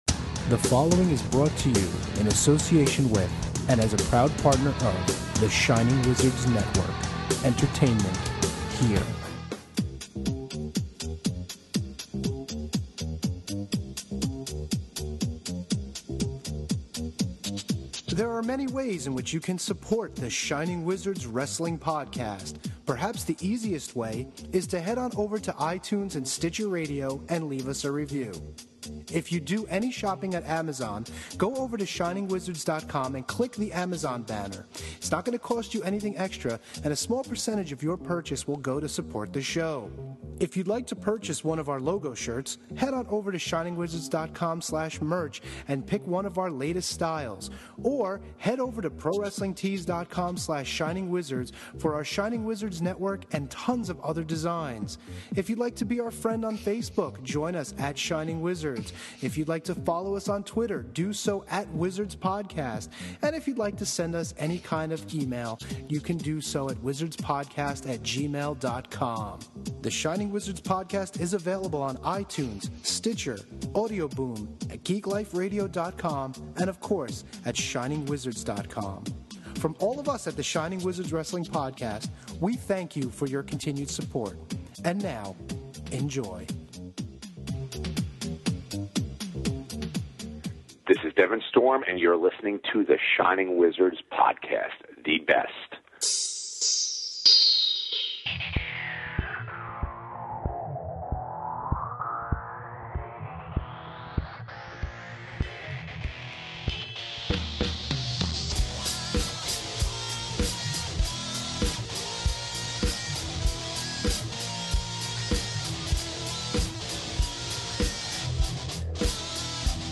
impromptu Skype conversation about all things WrestleMania, Raw, and PWS.